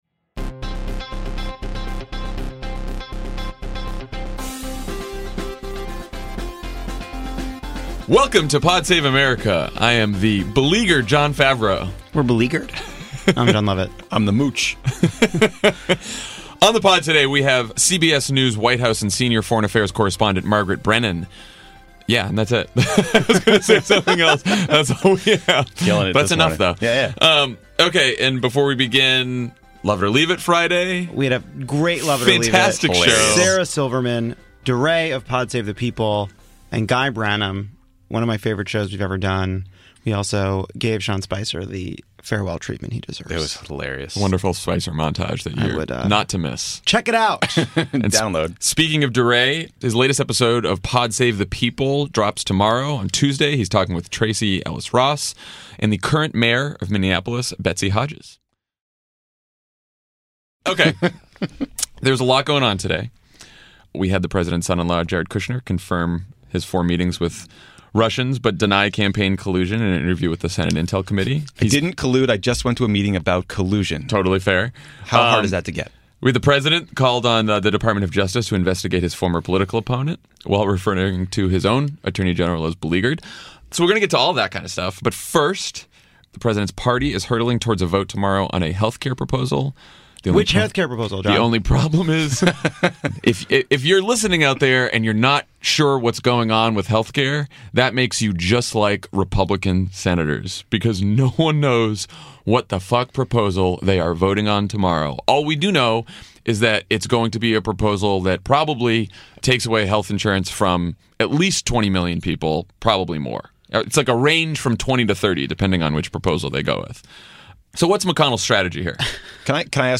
Republicans have no idea what health care bill they’re voting on, the Mooch lets Trump be Trump, and the Democrats unveil a new slogan and agenda. Then CBS News' Margaret Brennan joins Jon, Jon, and Tommy to talk about the Administration’s foreign policy challenges.